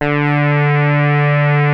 OSCAR B2  5.wav